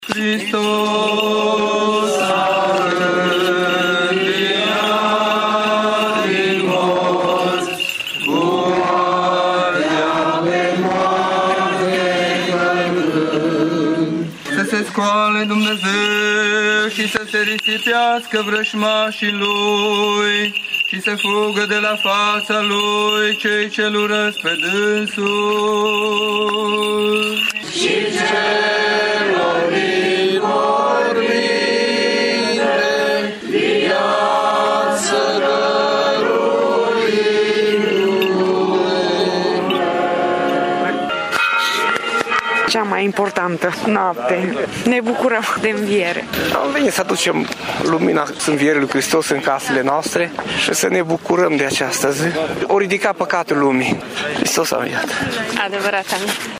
În toate bisericile ortodoxe și greco-catolice din oraş a fost săvârşită slujba de Înviere prin care s-a celebrat biruinţa vieţii asupra morţii.
creştinii au ieşit pe străzi cu lumânări în mâini, cântând Imnul Învierii.